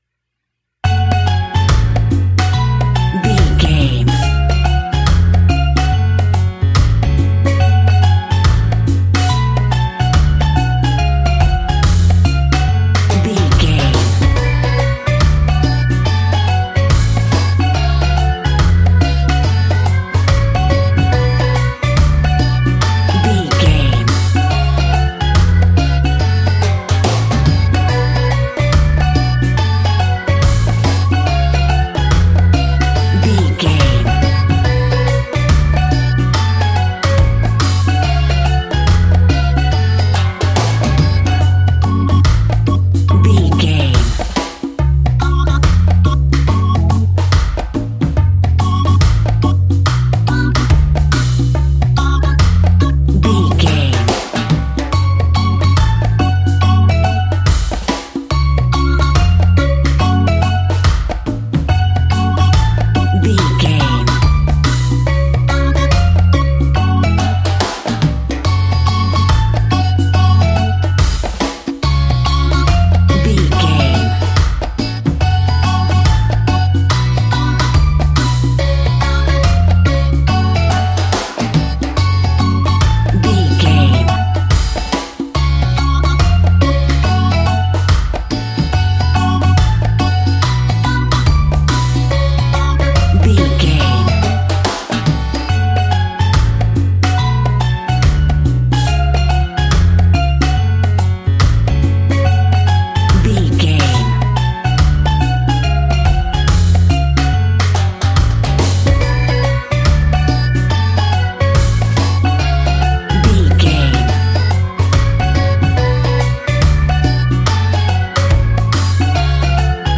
Aeolian/Minor
F#
steelpan
drums
percussion
bass
brass
guitar